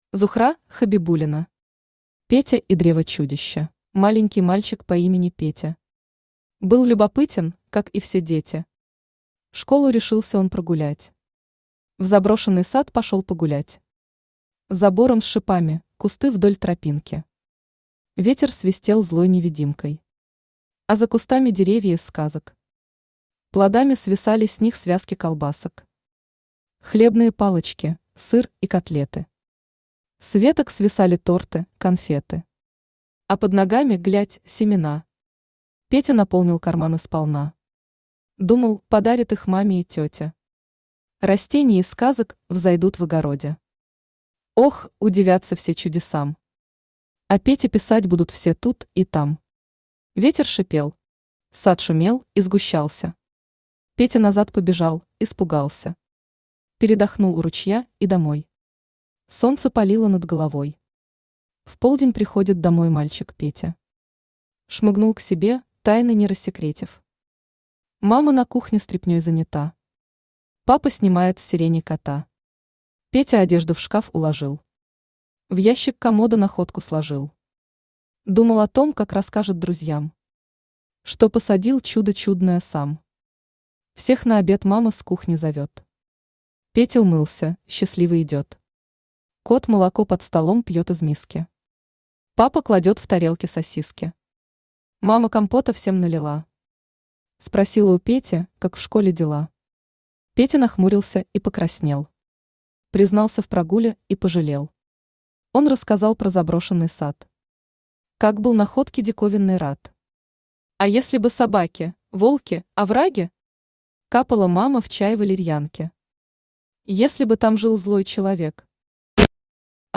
Аудиокнига Петя и древо-чудища | Библиотека аудиокниг
Aудиокнига Петя и древо-чудища Автор Зухра Хабибуллина Читает аудиокнигу Авточтец ЛитРес.